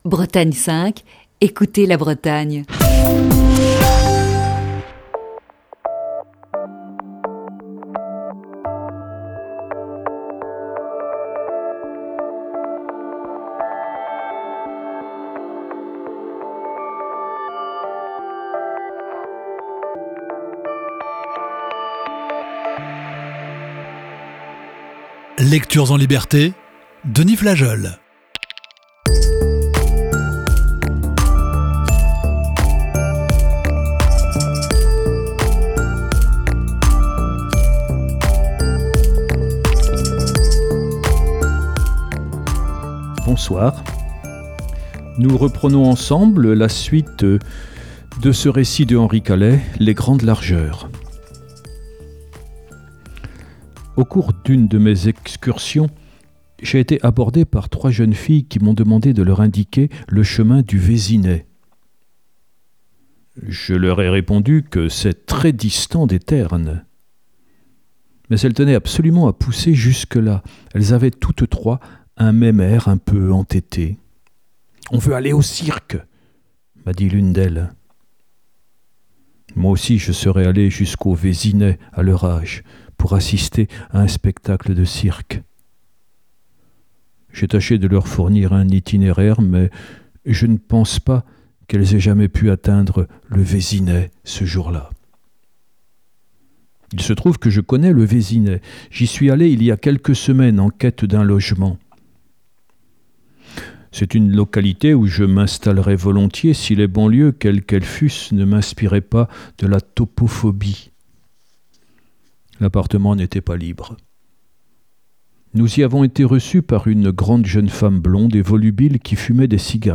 Émission du 22 octobre 2020.